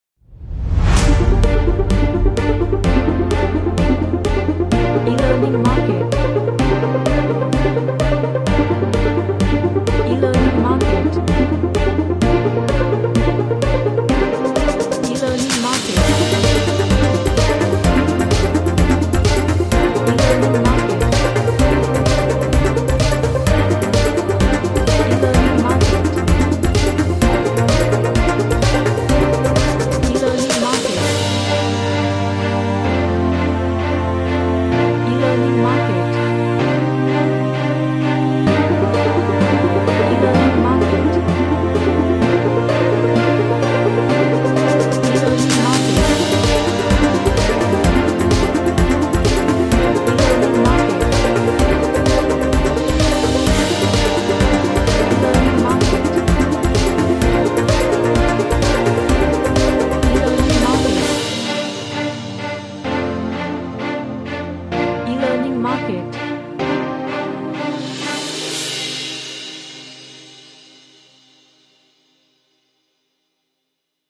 A track For Rapid News Broadcast.
Serious